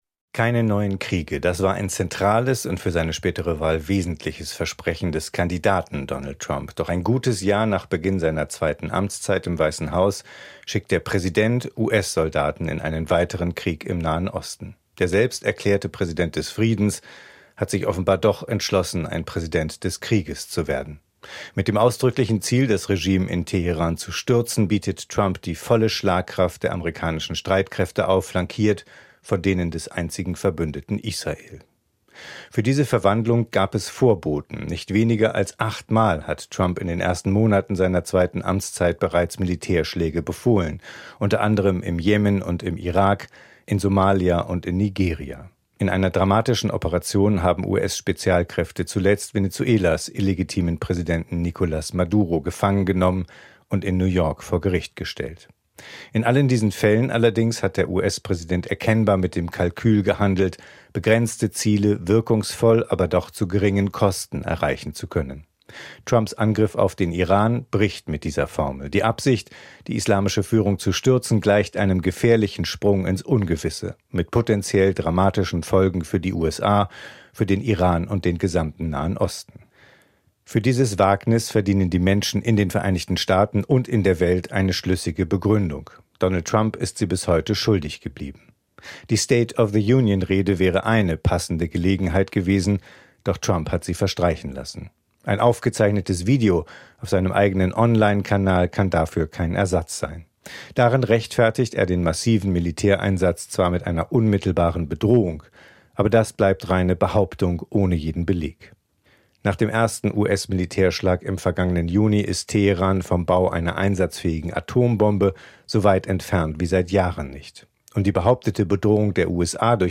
Kommentar: Trumps Krieg gegen Iran